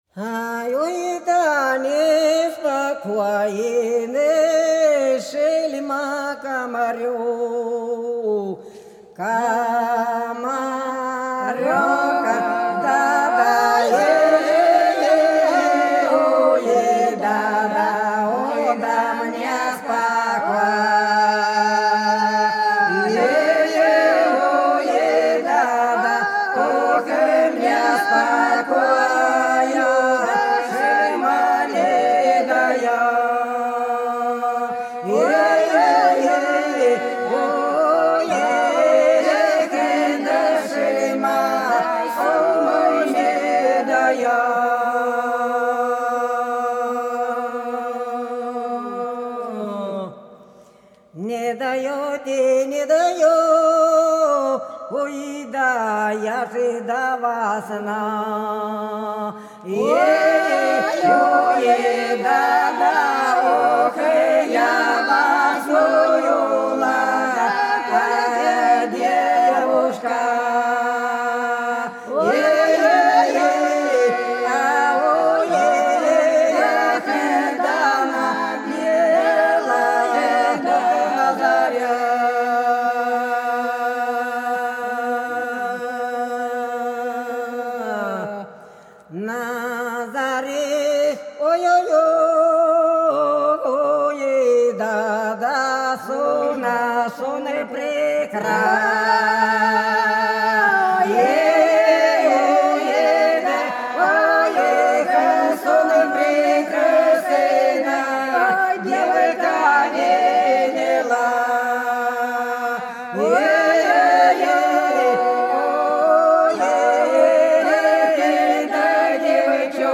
Белгородские поля (Поют народные исполнители села Прудки Красногвардейского района Белгородской области Ой, да неспокойный, шельма, комарёк - протяжная